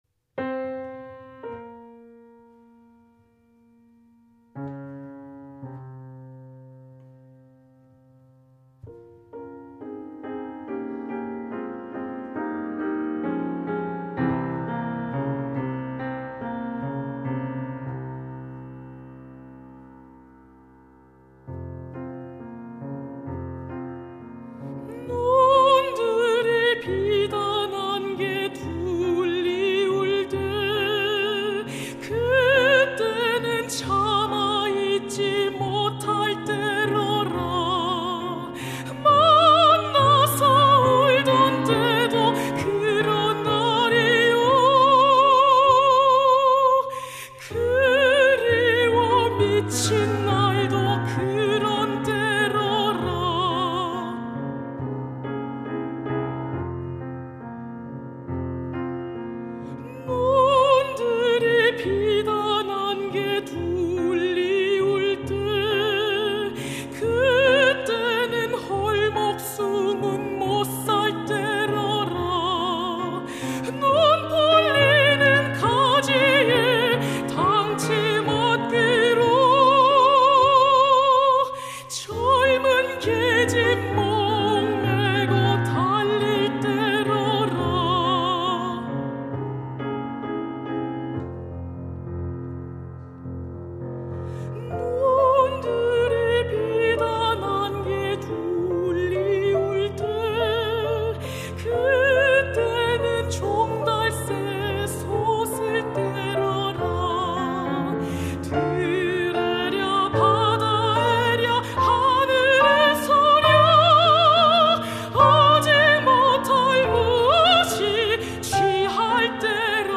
메조소프라노